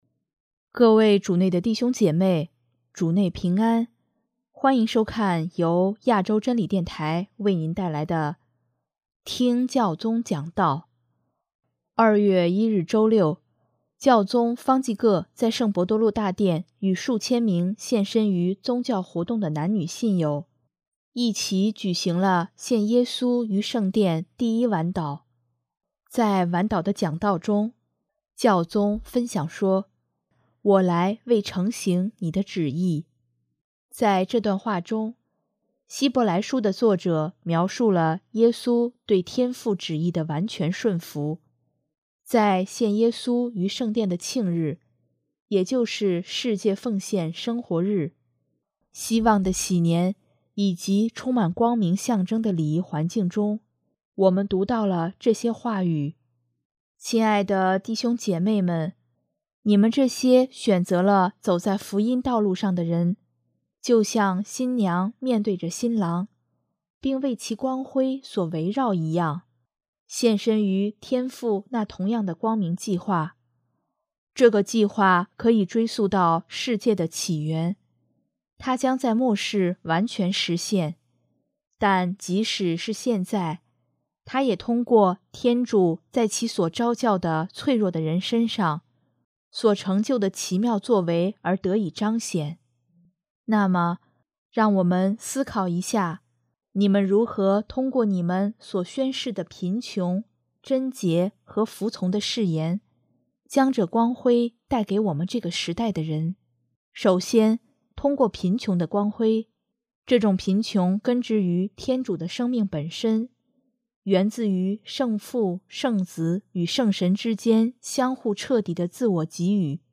2月1日周六，教宗各在圣伯多禄大殿与数千名献身于宗教活动的男女信友一起举行了献耶稣于圣殿第一晚祷。